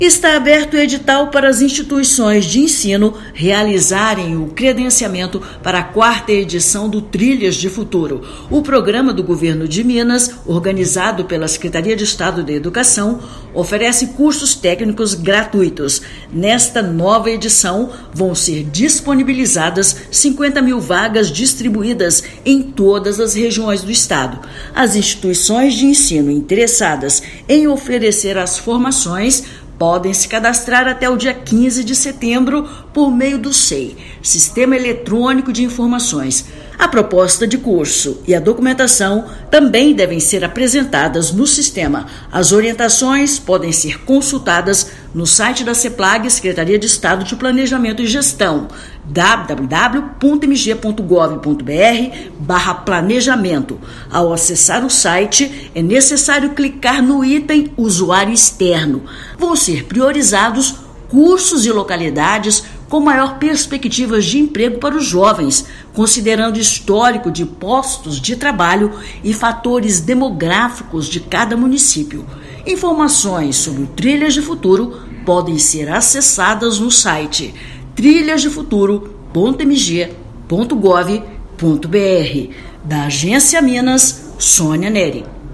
Credenciamento para a 4ª edição do maior programa de qualificação profissional de Minas Gerais pode ser realizado até 15/9. Ouça matéria de rádio.